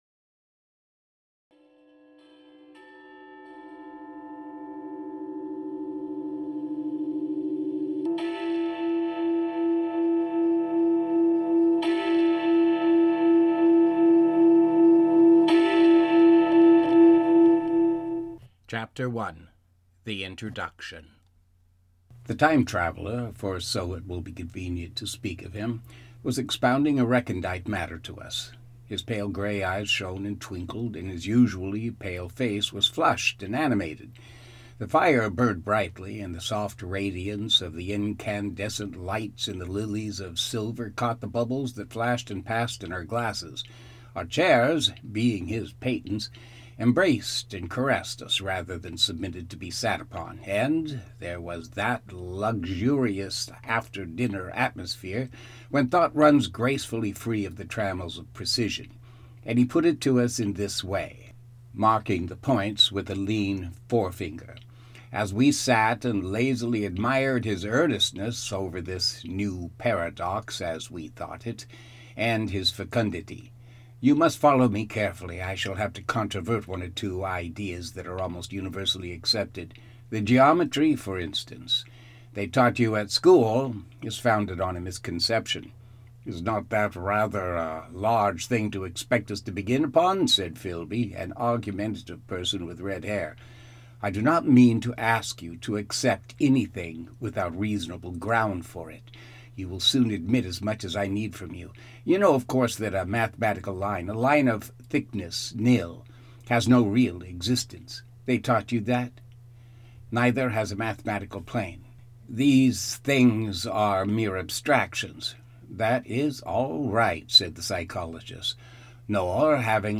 Read by Kennedy Center Award winning actor